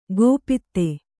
♪ gōpitte